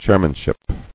[chair·man·ship]